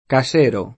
[ ka @$ ro ; sp. ka S% ro ]